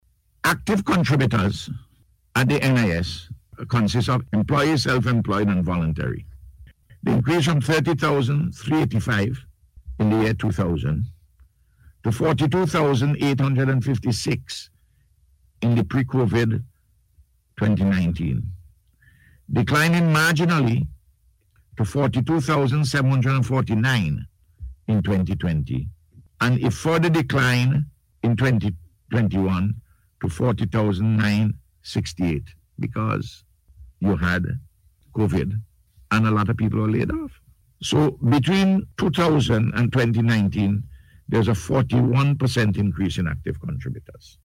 Prime Minister, Dr. Ralph Gonsalves provided an update on the performance of the NIS on NBC’s Face to Face Program this morning